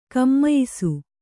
♪ kammayisu